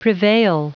Prononciation du mot prevail en anglais (fichier audio)